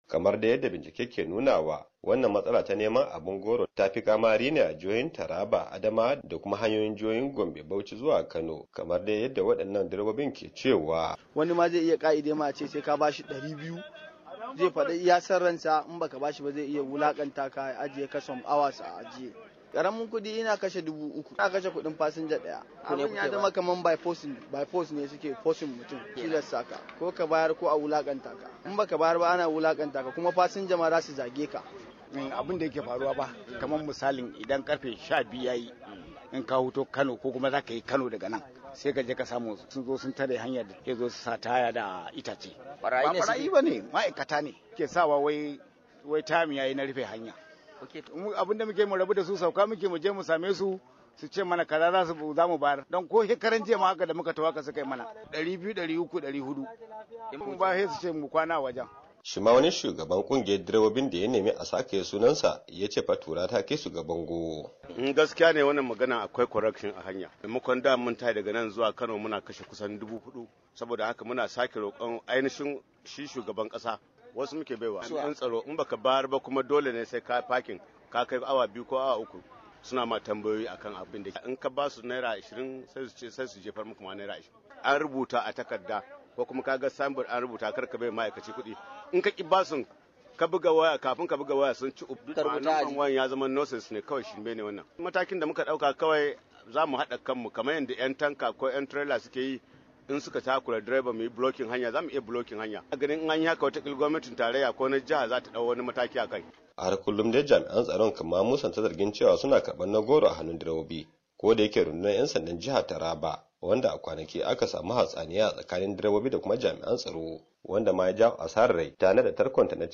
Cin hanci da rashawa ya zama ruwan dare a tsakanin jami’an tsaron Najeriya kamar yadda wasu Fasinjoji suka bayyana. Direbobin haya sun kawo misalin yadda ake takura musu a hanyoyinsu na zuwa garuruwa daban-daban.